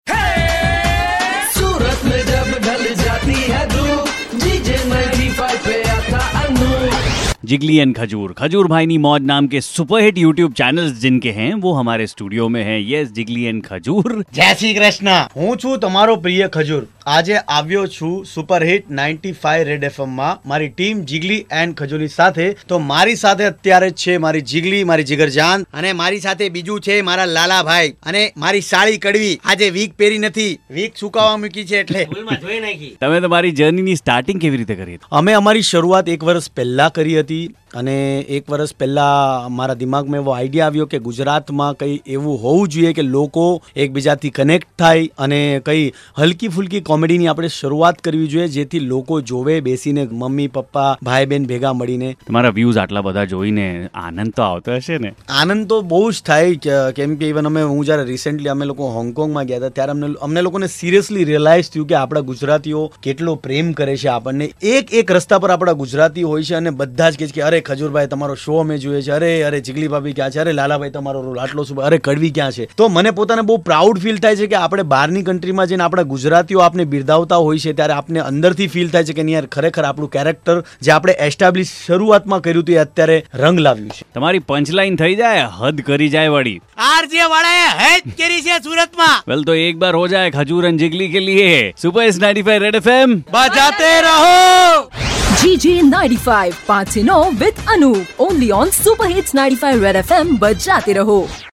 during their visit to Red FM Studio